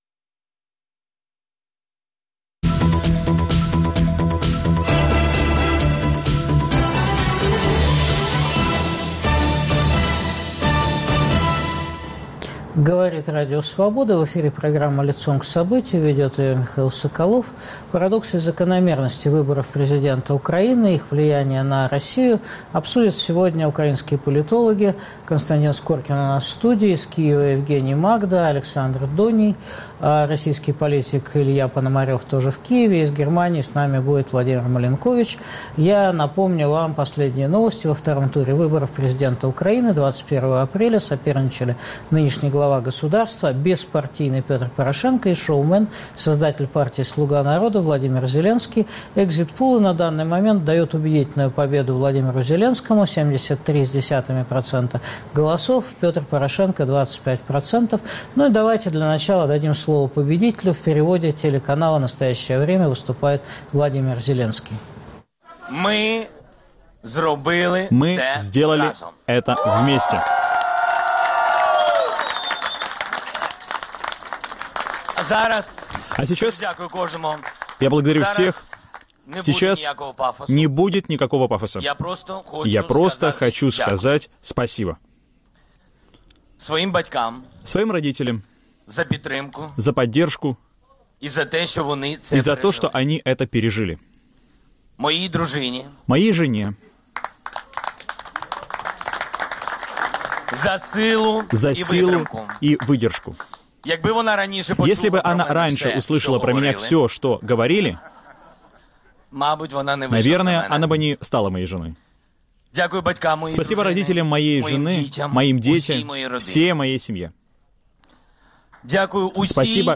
Парадоксы и закономерности выборов президента в Украине, их влияние на РФ обсуждают политологи